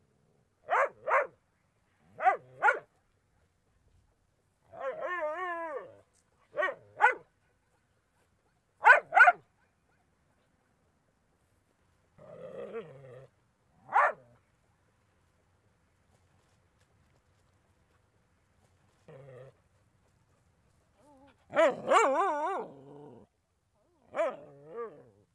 Dog_Small_barking-01.wav